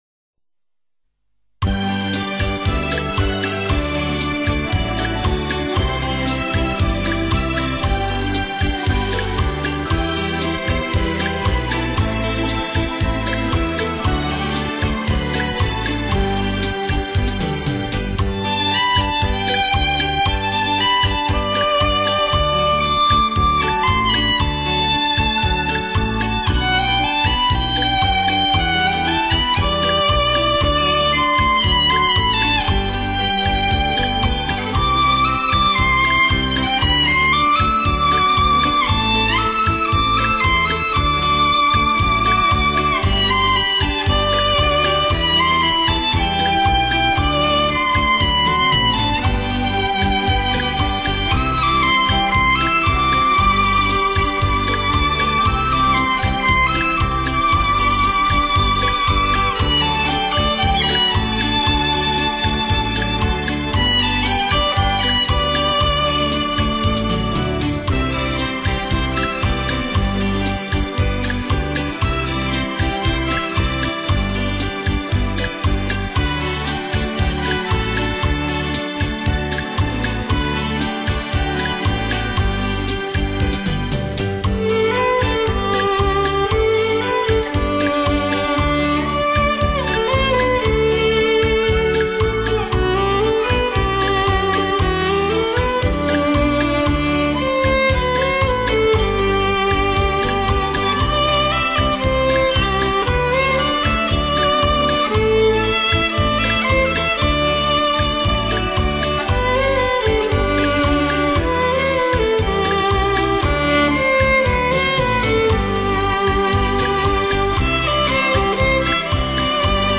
悠扬的琴声给你片刻的欢愉